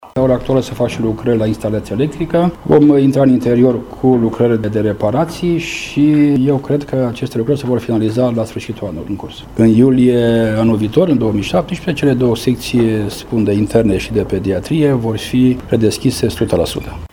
Lucrările actuale fac parte dintr-o primă etapă, care va fi finalizată anul viitor, după cum a precizat primarul muncipiului Săcele, Virgil Popa: